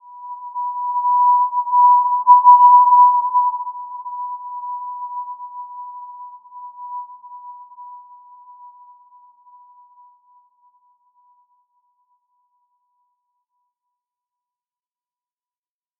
Simple-Glow-B5-mf.wav